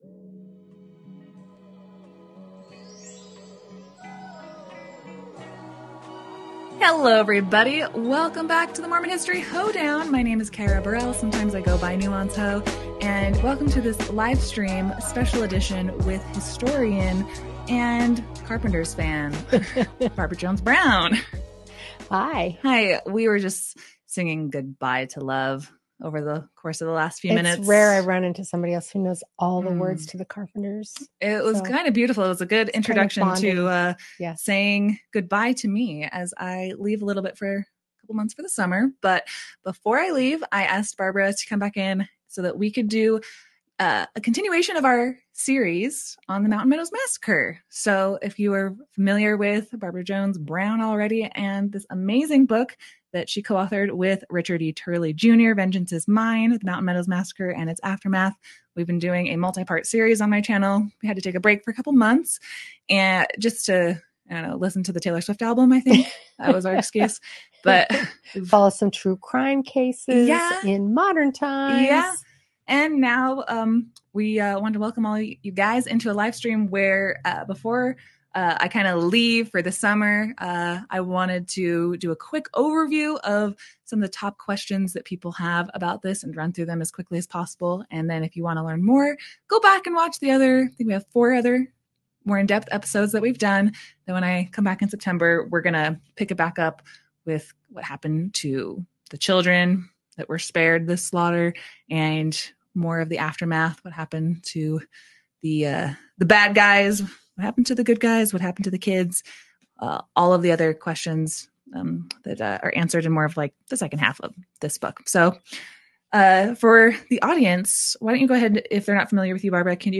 In this live steam